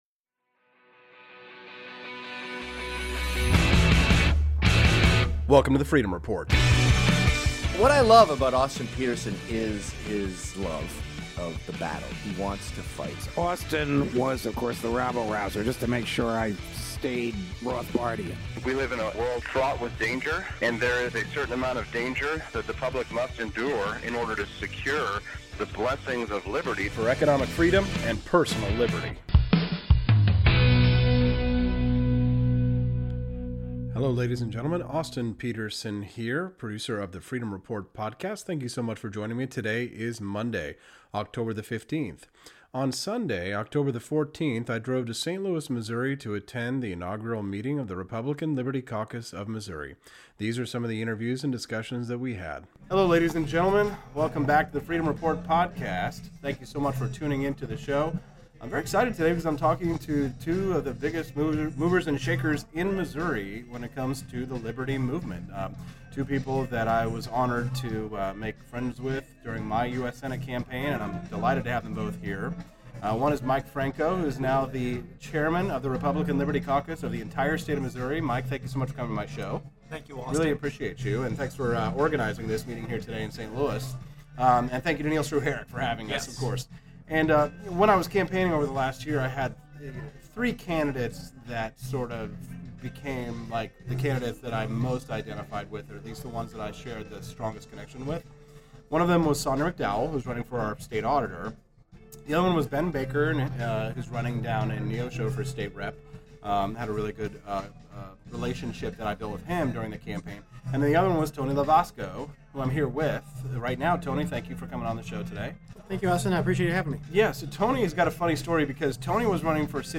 Facebook Twitter Headliner Embed Embed Code See more options For years the state of Missouri has lacked a strong statewide organization of liberty Republicans. That all changed this weekend when on Sunday, September the 14th, the Republican Liberty Caucus of Missouri held its kickoff meeting. Former US Senate candidate Austin Petersen interviews the attendees and candidates who arrived to discuss their plans to elect more liberty loving candidates at the state level.